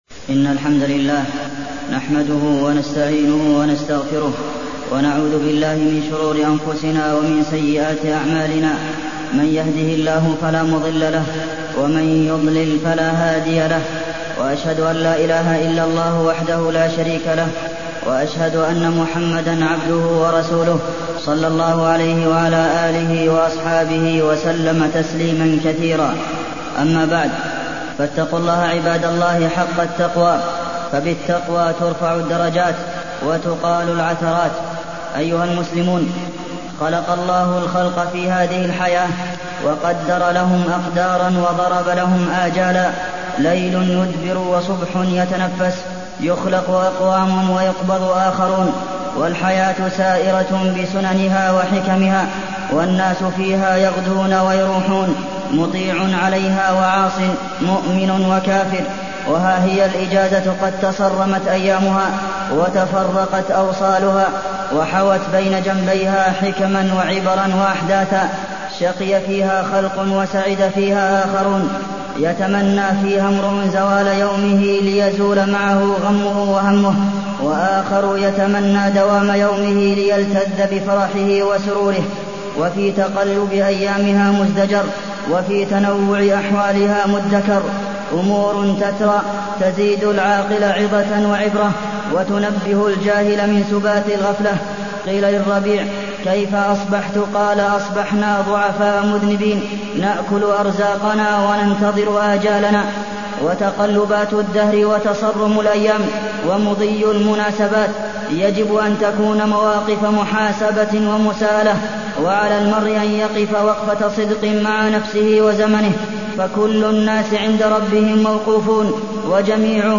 تاريخ النشر ١٩ جمادى الآخرة ١٤٢٢ هـ المكان: المسجد النبوي الشيخ: فضيلة الشيخ د. عبدالمحسن بن محمد القاسم فضيلة الشيخ د. عبدالمحسن بن محمد القاسم قضاء الأجازة بين النفع والضرر